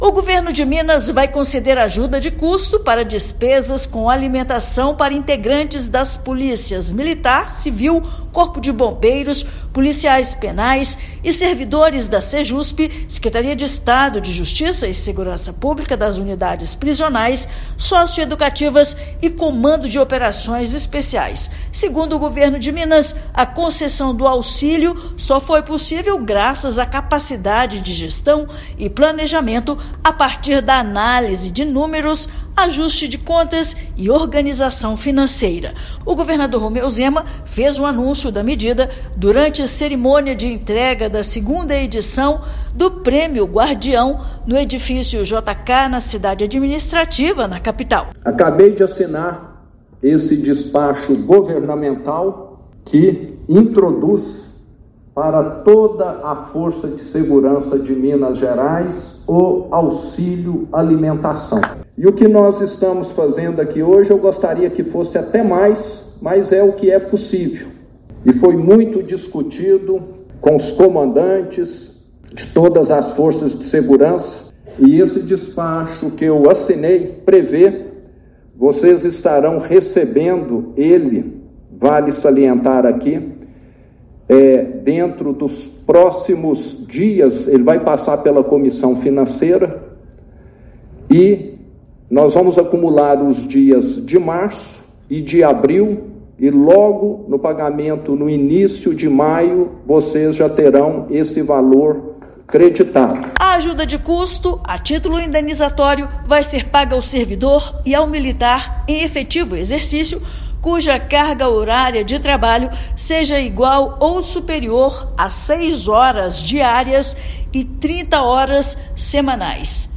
Ajuda de custo para alimentação tem imediato impacto positivo de até 23% no salário de servidores da base das Forças de Segurança. Ouça matéria de rádio.